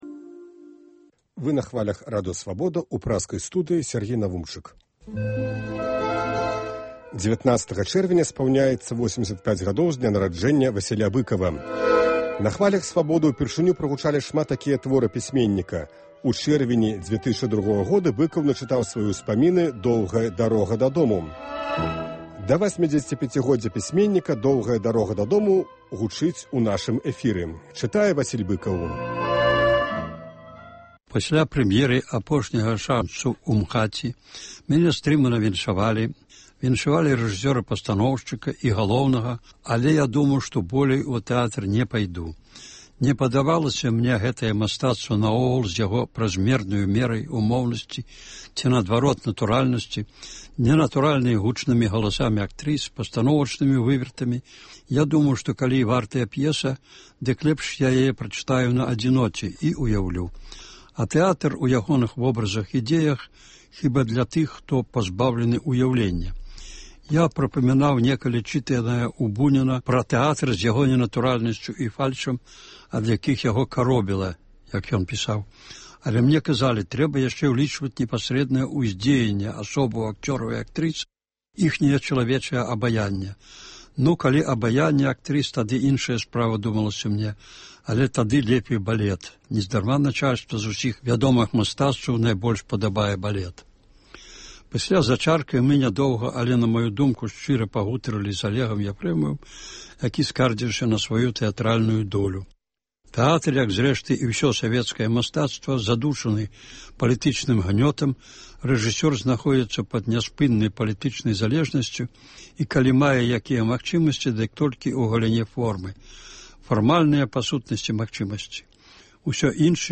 З архіву "Свабоды": Васіль Быкаў чытае "Доўгую дарогу дадому"